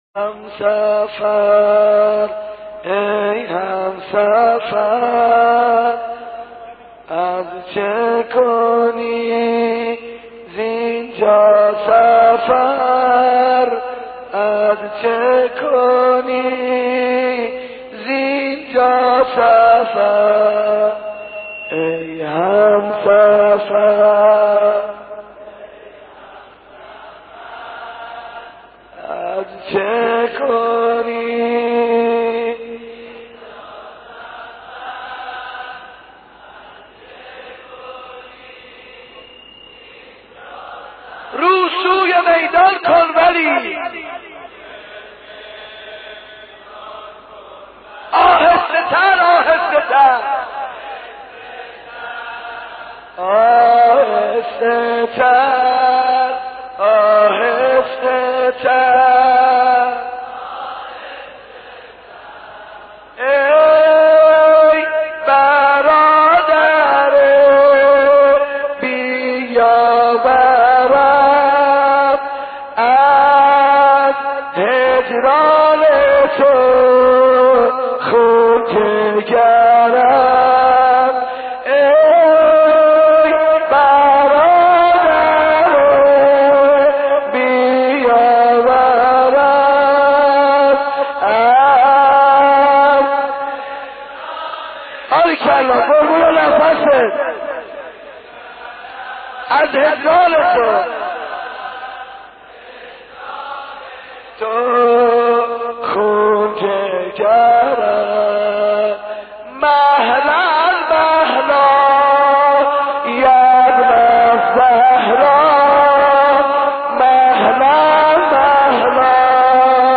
در عاشورای حسینی
مداحی قدیمی